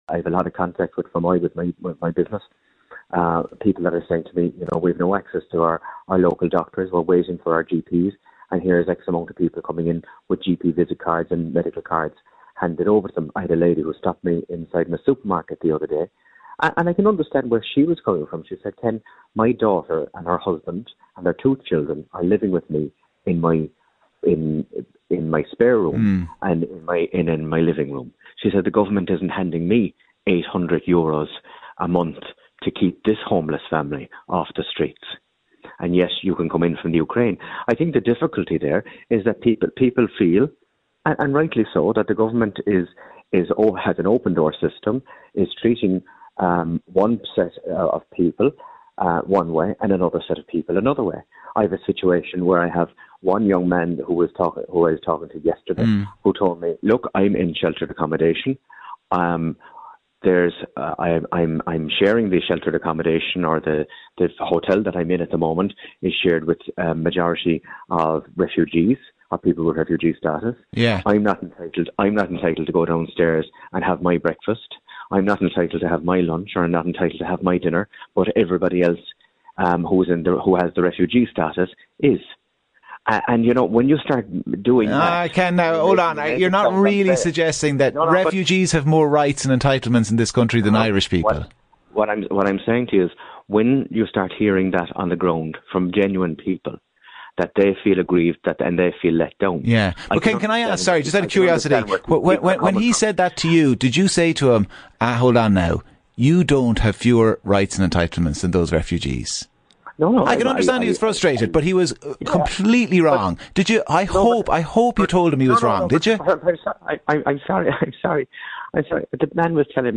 NewsTalk